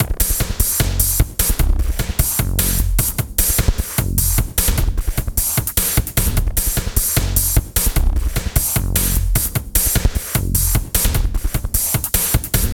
B + D LOOP 3 1.wav